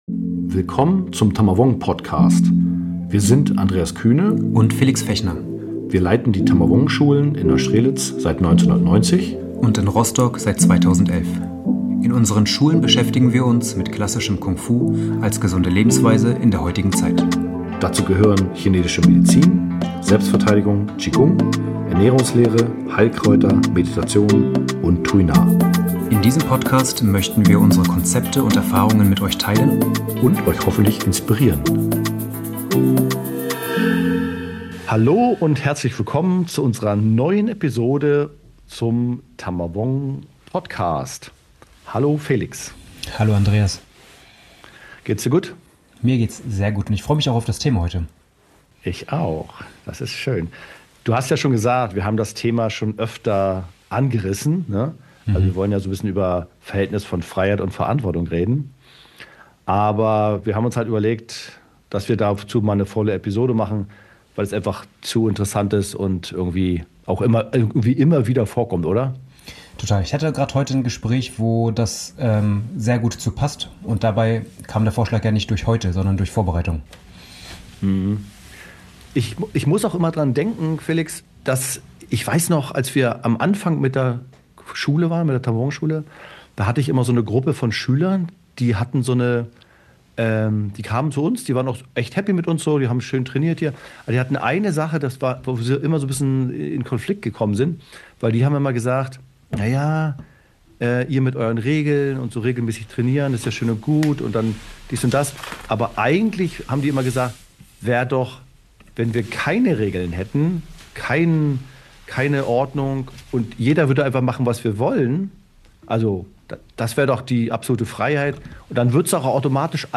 Ein Gespräch über Freiheit als Kompetenz, über die Fähigkeit zur Transformation – im Alltag, im Training und im Leben.